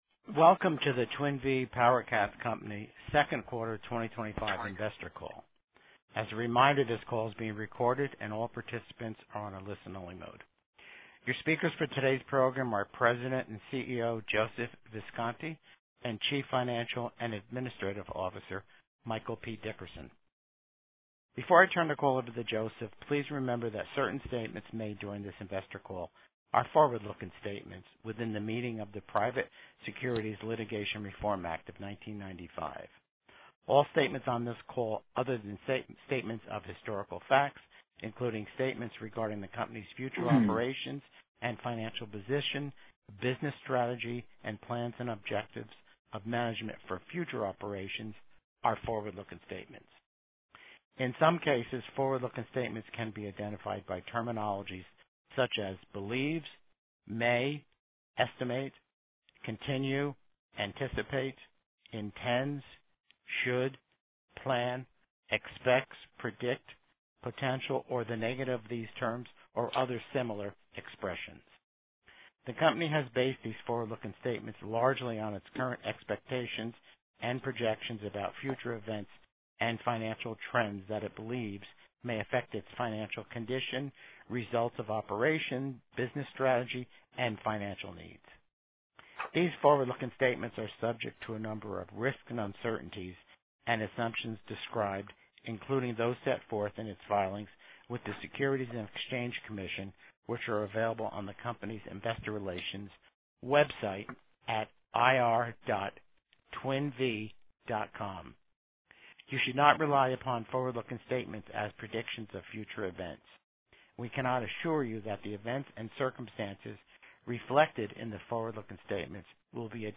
Twin Vee PowerCats Co. Second Quarter 2025 Financial Results Conference Call